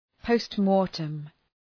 Προφορά
{,pəʋst’mɔ:rtəm}